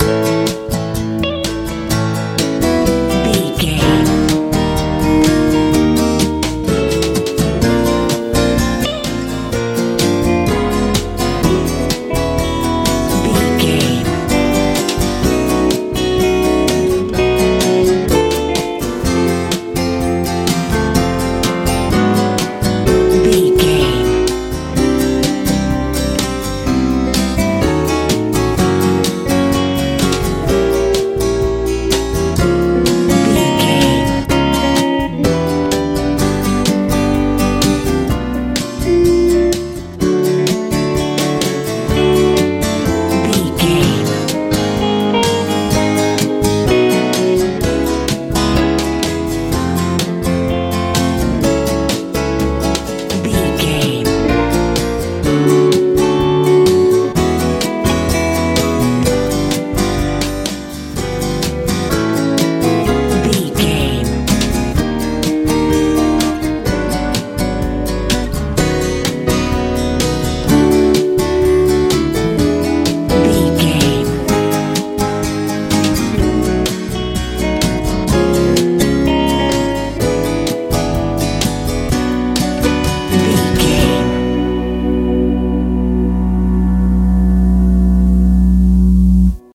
soft rock
Ionian/Major
light
mellow
electric guitar
acoustic guitar
bass guitar
drums
tranquil
soft